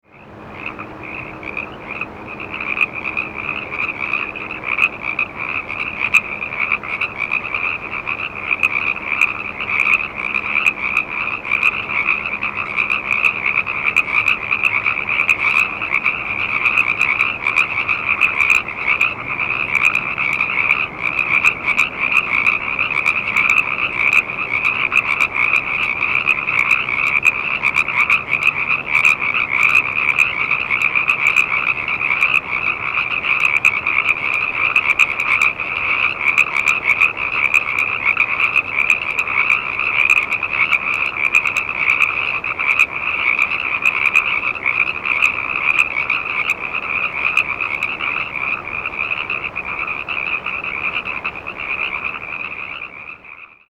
The Baja California Treefrog produces two different kinds of very loud advertisement calls: a two-part, or diphasic call, typically described as rib-it, or krek-ek, with the last syllable rising in inflection, and a one-part, or monophasic call, also called the enhanced mate attraction call.
Diphasic (two-part) Call
This call is produced during the day and at night, often in large choruses.
Sound This is :53 of a chorus of frogs starting up then winding down recorded at night in Kern County on the banks of a river (shown to the right.)